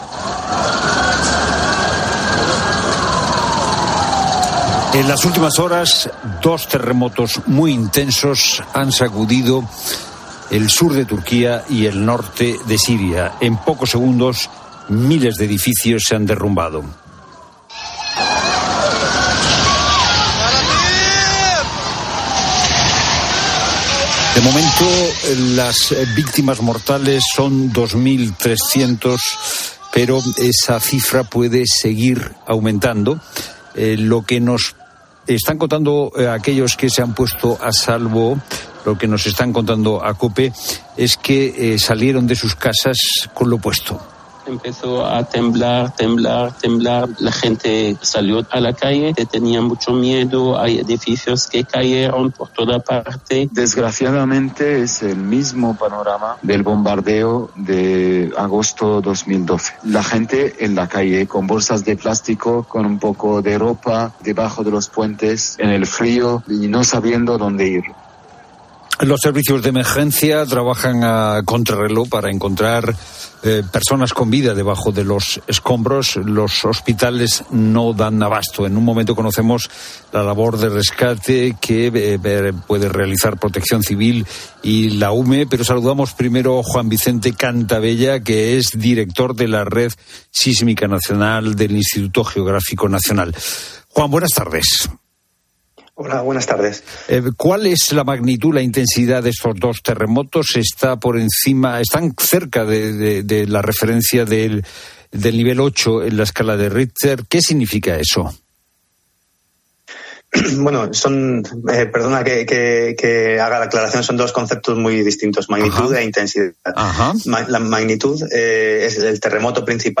Analizamos las consecuencias con un sismólogo, con el director general de Protección Civil y Emergencias del Ministerio del Interior y con un...